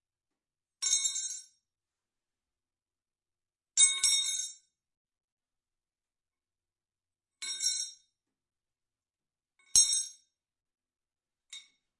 钢管落在混凝土上 " 34 扳手落在混凝土地上，室内，5次撞击
描述：将42扳手放在水泥地面上.RAW文件记录器：放大H6，带XY capsuelResolution：96/24
Tag: 拟音 铁匠 扳手 混凝土 金属 金属 地板 命中 冲击